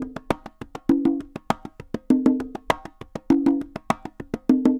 Congas_Salsa 100_4.wav